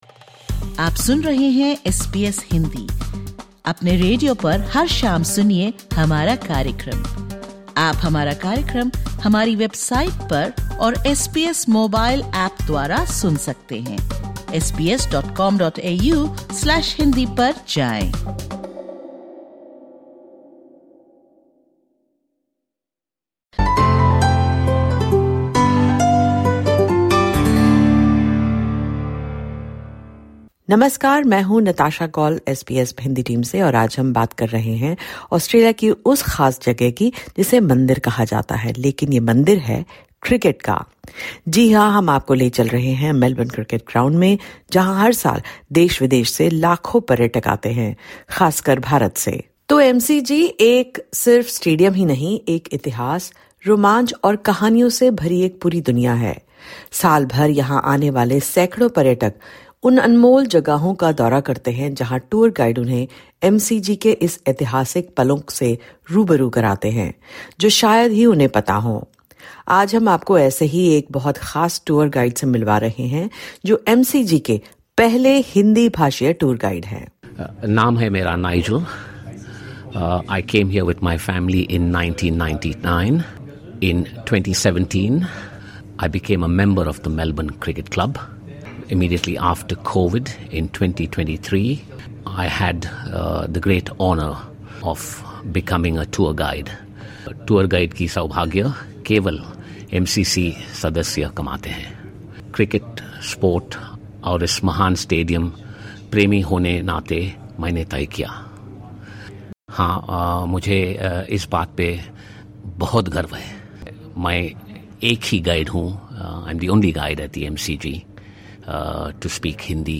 ( Disclaimer: The views/opinions expressed in this interview are the personal views of the individual.)